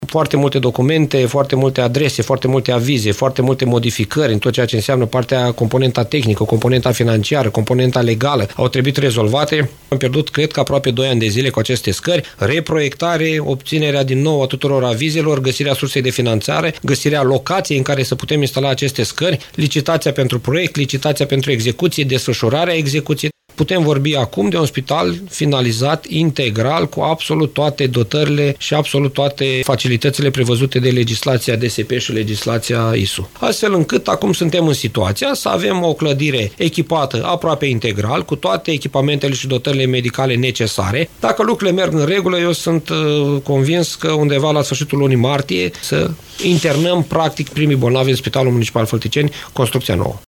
Primarul CĂTĂLIN COMAN a declarat postului nostru de radio că proiectul inițiual a fost modificat pentru a respecta legislația impusă de Direcția de Sănătate Publică și de Inspectoratul pentru Situații de Urgență.